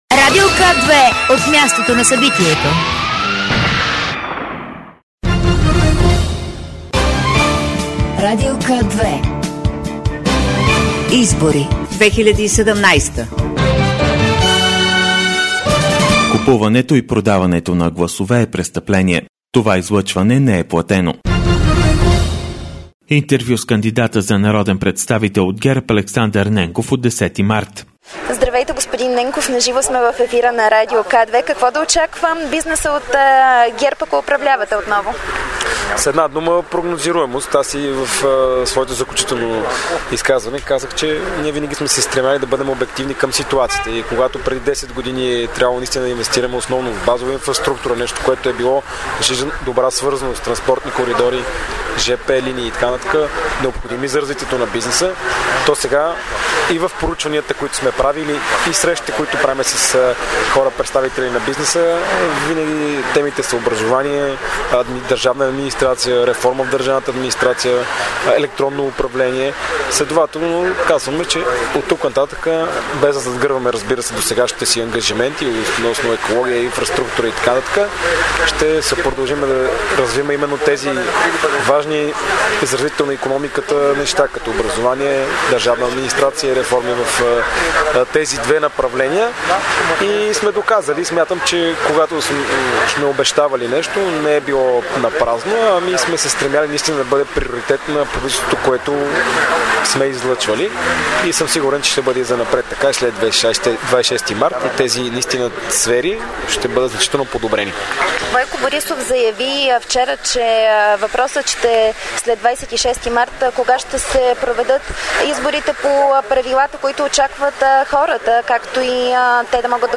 12.55 - Интервю с водача на листата на ГЕРБ в 23 МИР Томислав Дончев. - директно от мястото на събитието  (парк - хотел „ Витоша")